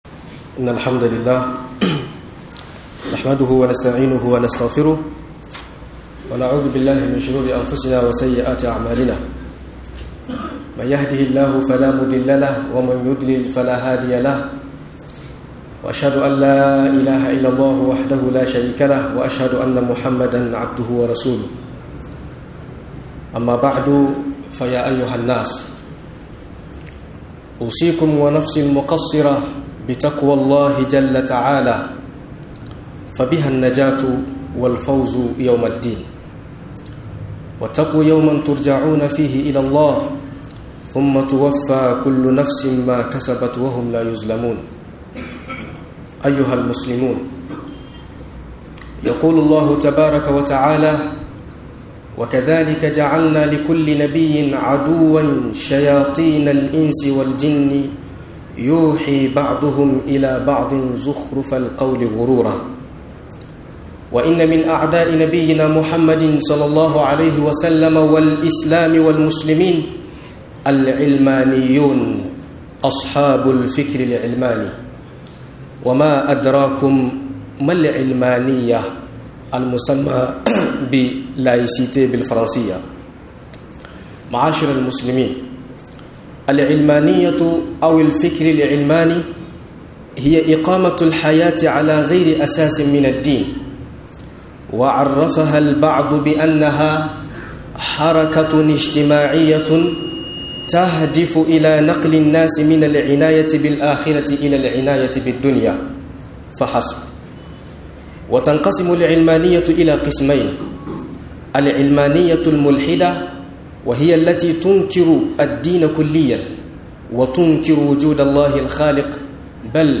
Laicité da tasirin ta a Niger - MUHADARA